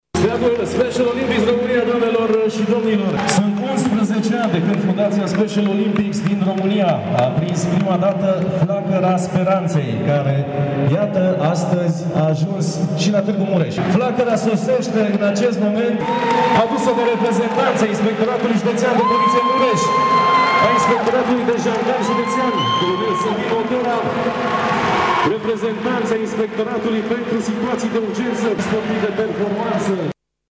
Ieri, în Sala Polivalentă din Tîrgu-Mureș a avut loc festivitatea de inaugurare a unei noi ediții a Special Olympics România, competiție rezervată persoanelor cu dizabilitați intelectuale.
Au participat aproape 300 de persoane dintoată țara, punctul culminant fiind dat de arborarea steagului ”Special Olympics” si de aprinderea flăcării speranței.